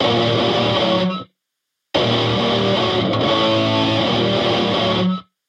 This is then mixed as a third “ghost” or subliminal guitar part in the middle of the mix.
Zystrix Recording Metal Guitar - two guitars and ghost.mp3